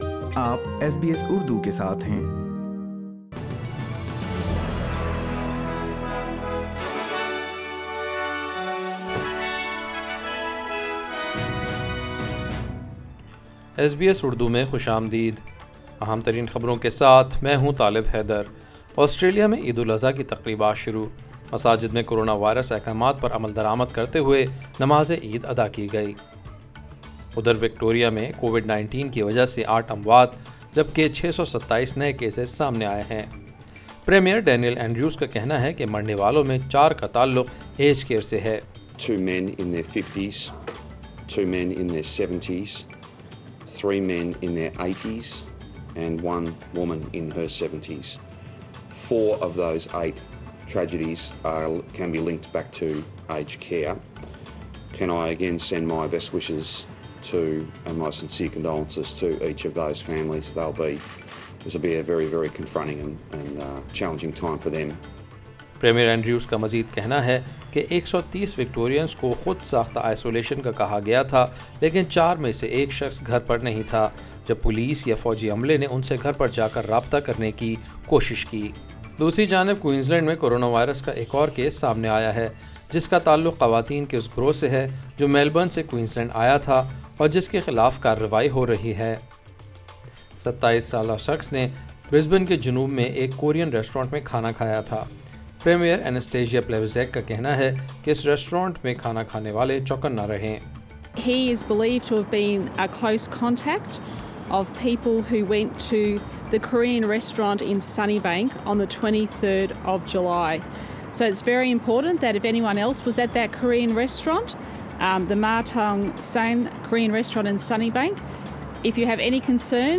daily_news_31.7.20.mp3